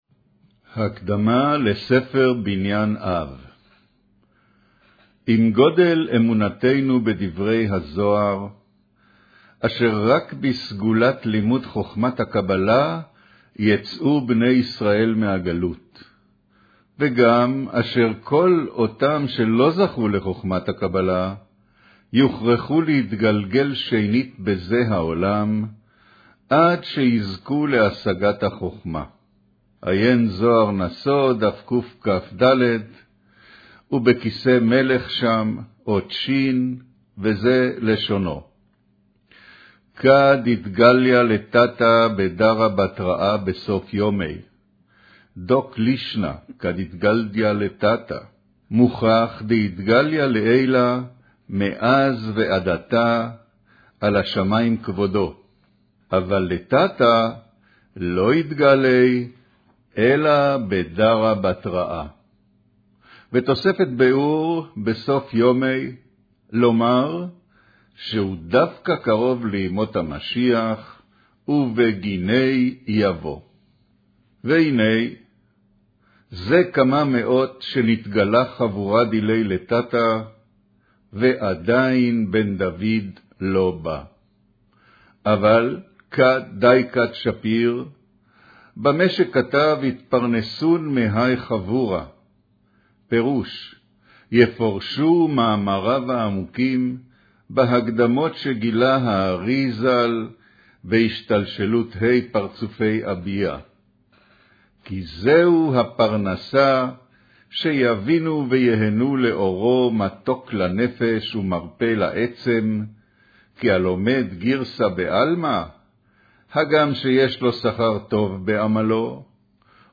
אודיו - קריינות הקדמה לספר בנין אב